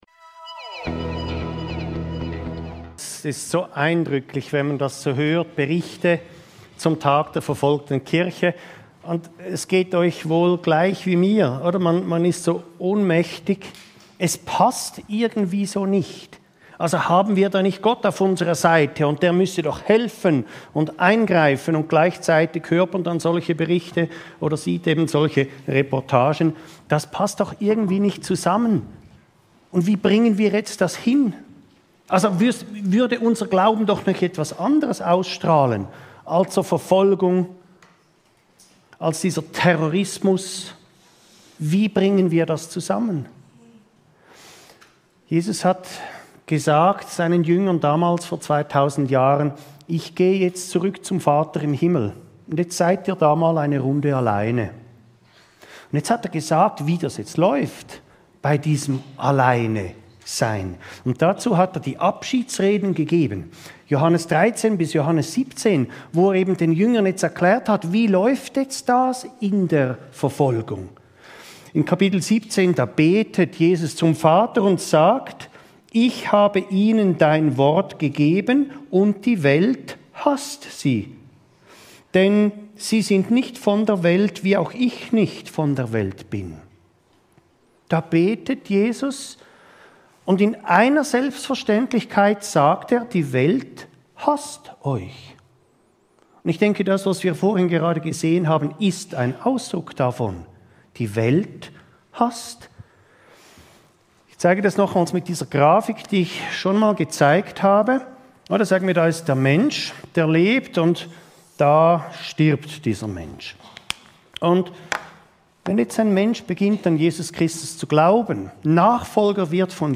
Verfolgung als Teil des Christseins ~ Your Weekly Bible Study (Predigten) Podcast